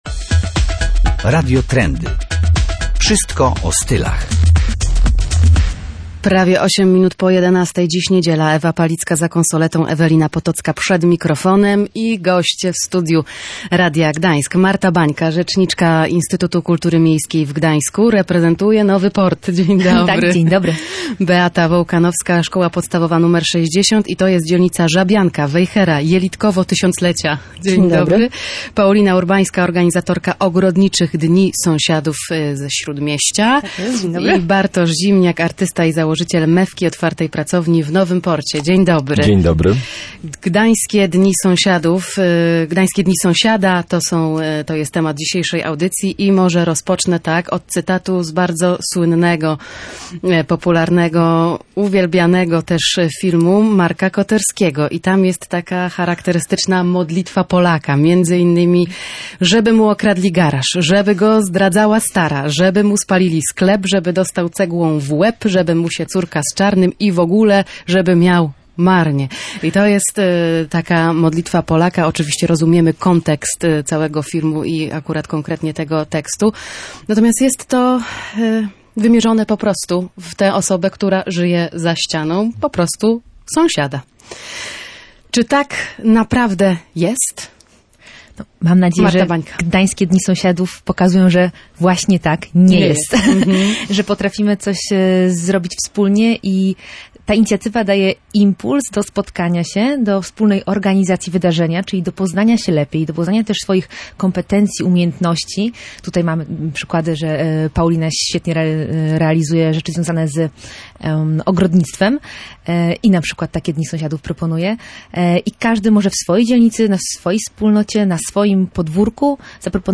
Poznawanie sąsiadów jest trendy – przekonują goście audycji Radio Trendy.